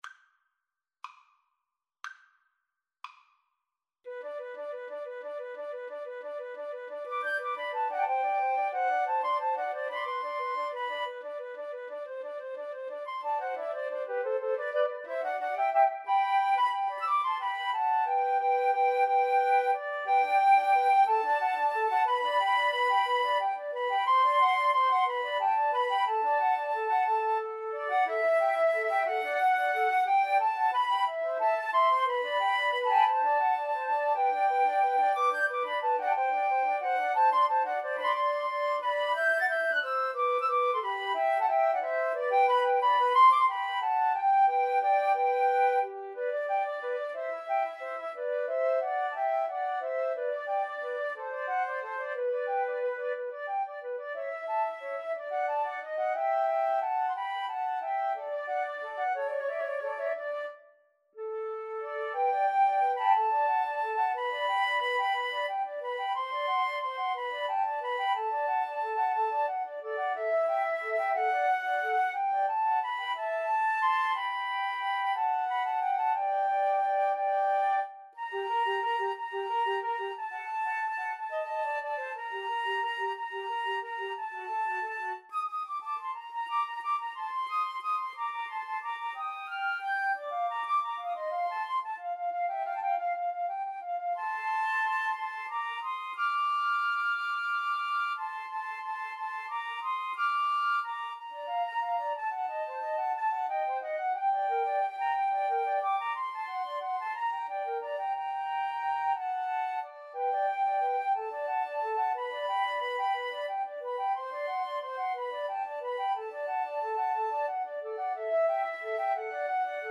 Flute 1Flute 2Flute 3
6/8 (View more 6/8 Music)
G major (Sounding Pitch) (View more G major Music for Flute Trio )
Molto lento .=c. 60
Classical (View more Classical Flute Trio Music)